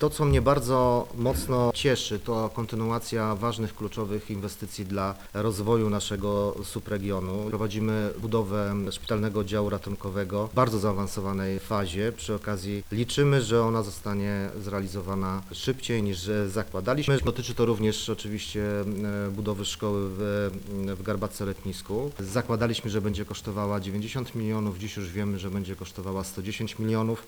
Jak zauważa wicemarszałek Rafał Rajkowski, te środki pomogą w realizacji wielu potrzebnych inwestycji i dodaje: